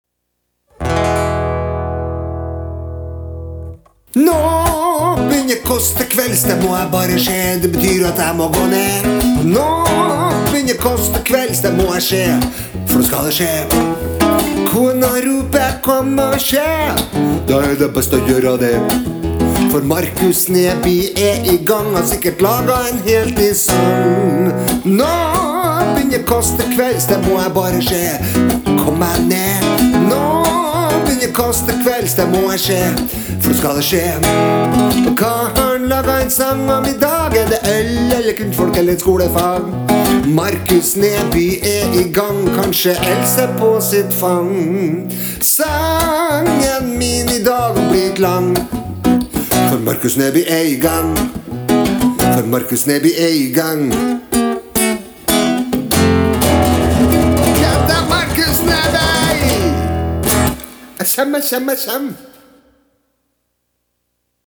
gitar